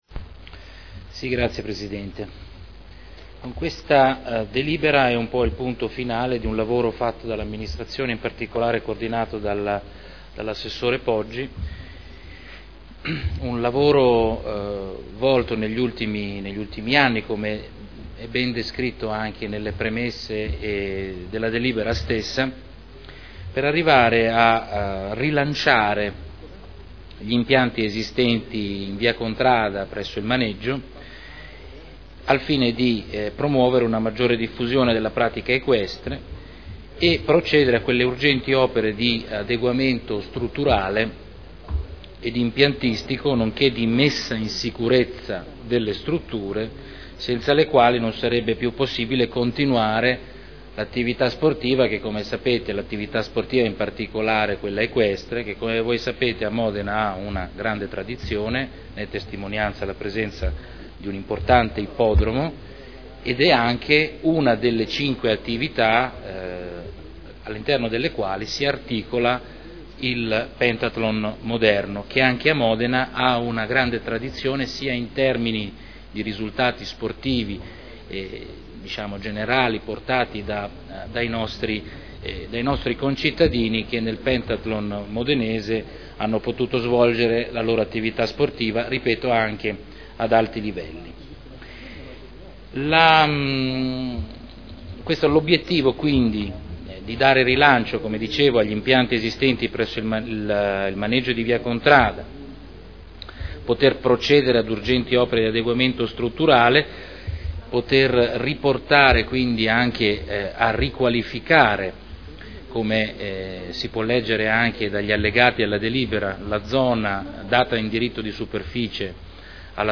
Salvatore Cotrino — Sito Audio Consiglio Comunale
Seduta del 30/05/2011. Dibattito su proposta di deliberazione: Proroga e integrazione del diritto di superficie assegnato a Equipenta Srl per sport equestri in Via Contrada